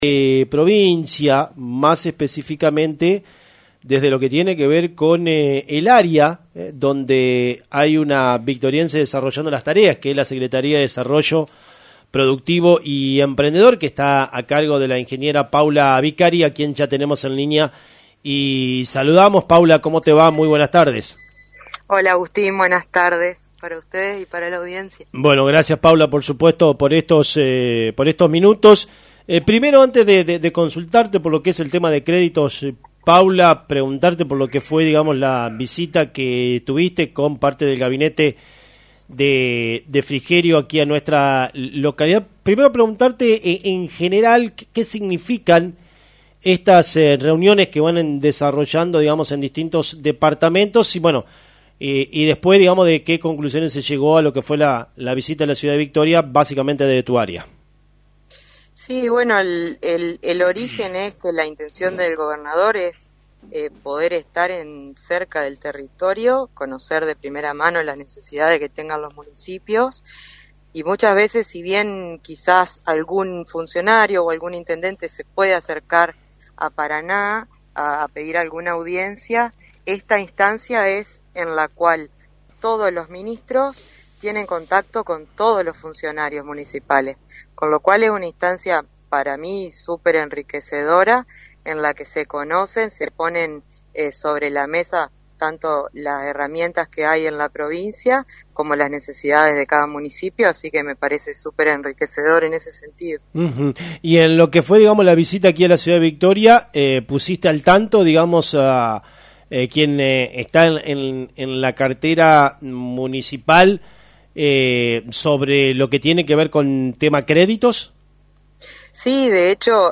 La Secretaría de Desarrollo Productivo y Emprendedor de la Provincia, liderada por la Ingeniera Paula Vicari, ha estado trabajando para acercar herramientas de financiamiento a los emprendedores. En una reciente entrevista con LT39, la funcionaria destacó la importancia de las reuniones que se están llevando a cabo en distintos departamentos, incluyendo una reciente visita a la ciudad de Victoria.